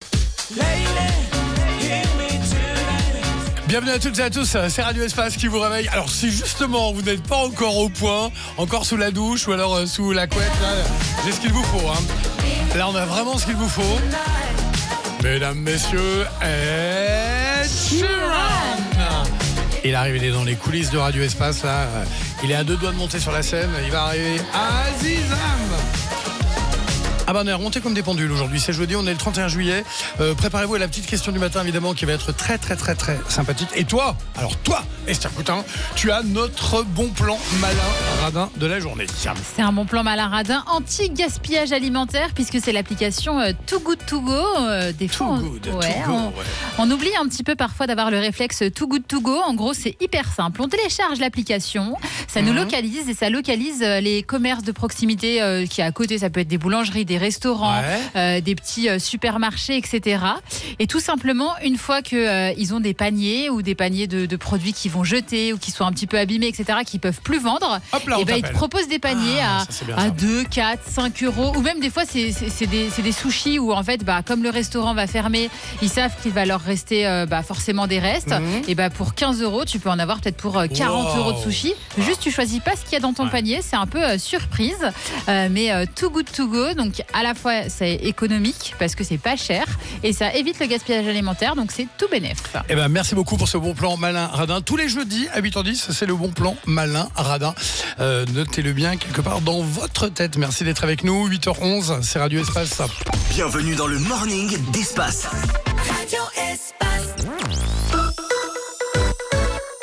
Duo matinale Radio Espace Bon plan
20 - 35 ans